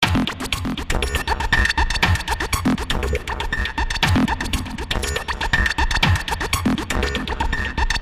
描述：电子猪的节拍
标签： 120 bpm Electro Loops Drum Loops 1.35 MB wav Key : Unknown
声道立体声